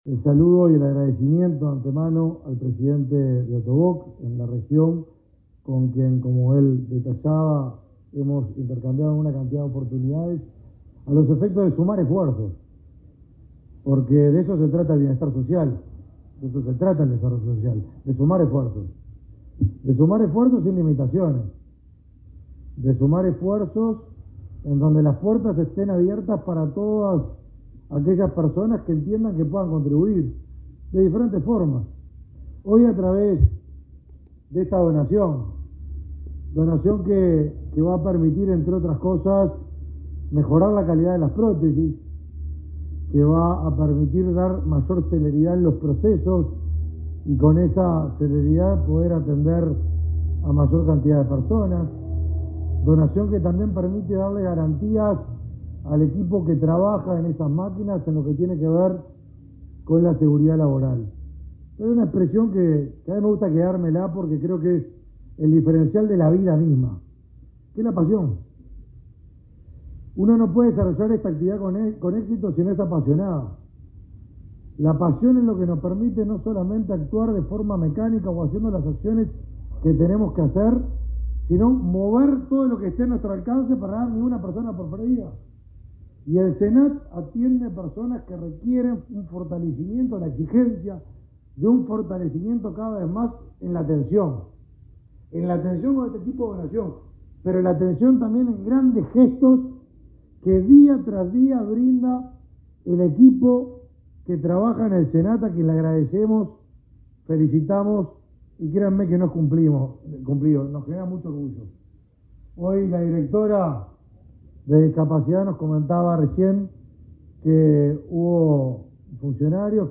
Palabras del ministro de Desarrollo Social y del secretario de Presidencia
El ministro Martín Lema y el secretario de Presidencia, Álvaro Delgado, participaron de la entrega de una donación de la empresa Ottobock Argentina a